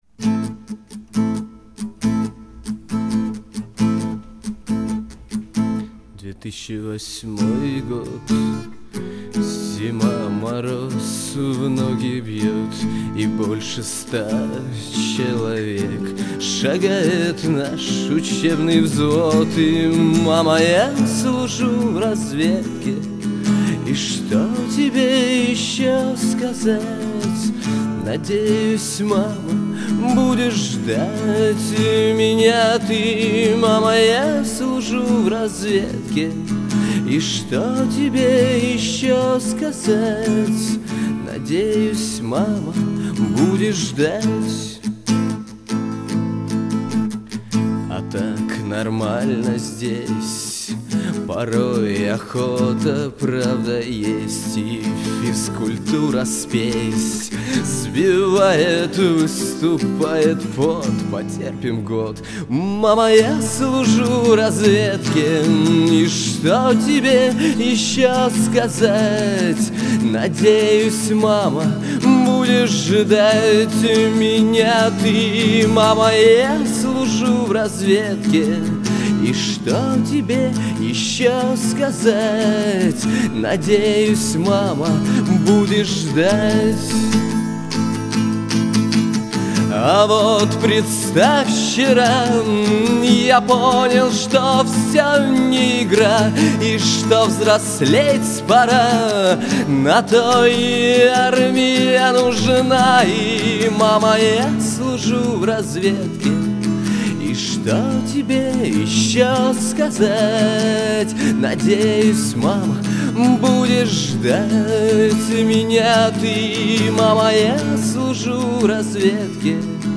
Гитара / армейские